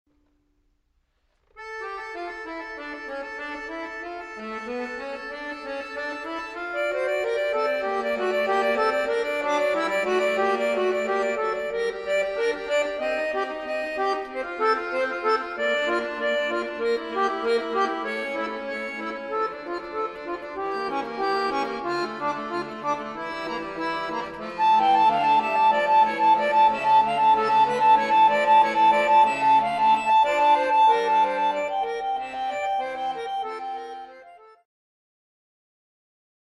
Fuga